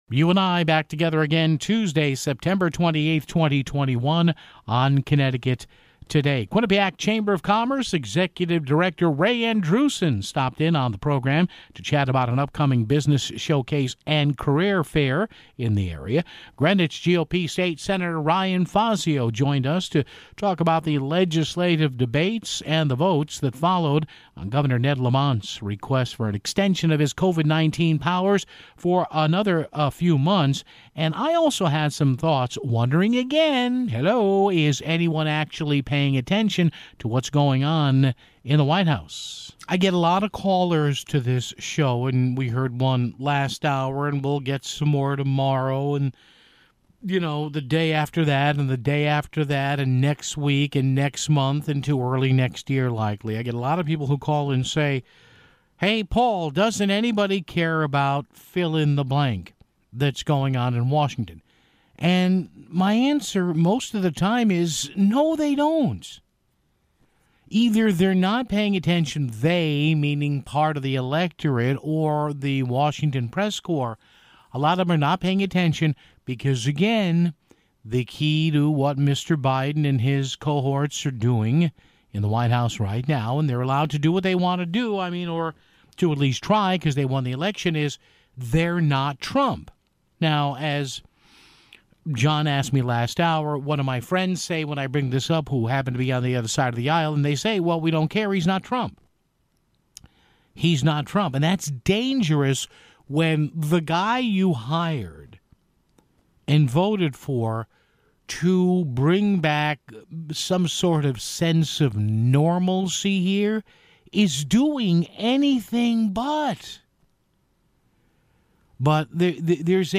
Greenwich GOP State Sen. Ryan Fazio joined us the talk about the legislative debate and votes on Gov. Ned Lamont's request for an extension of his Covid-19 executive powers for another six months (20:24).